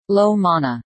Low_Mana.ogg